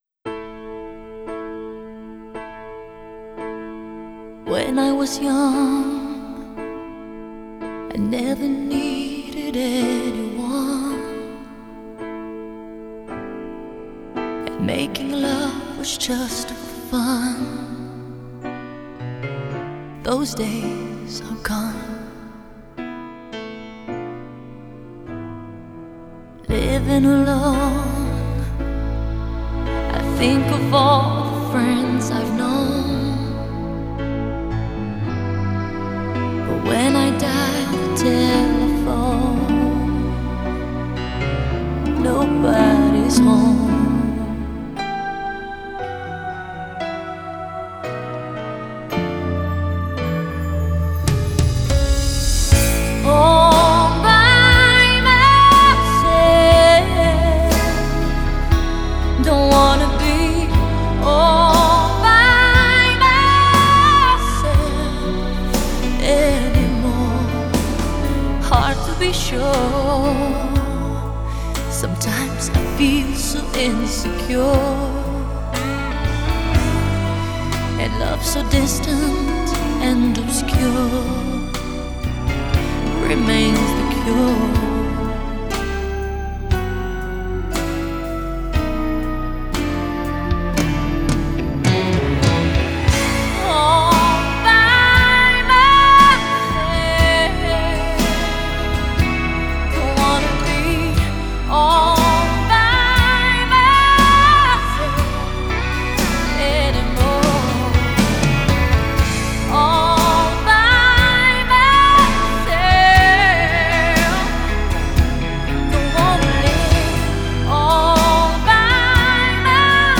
[Strings]
Piano
Bass
Drums
Guitar
Keyboards
Percussion